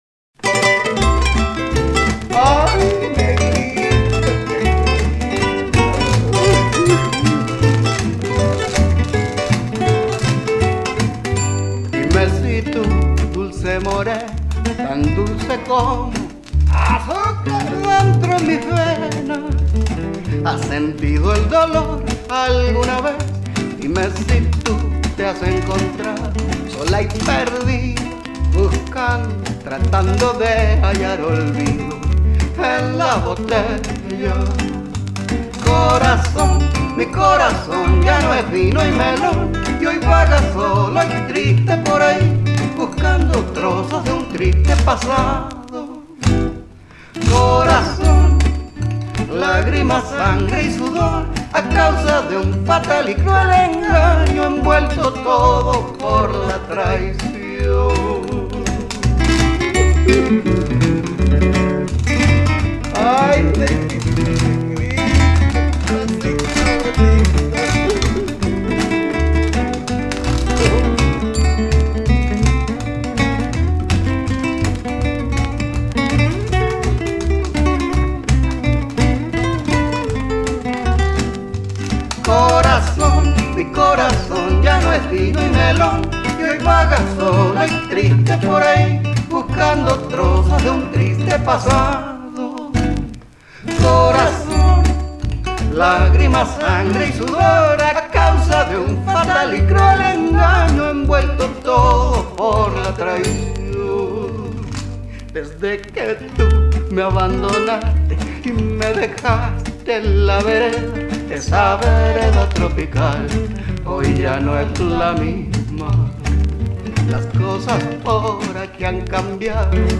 Bella Morena”, composición inspirada en el jazz guachaca y foxtrot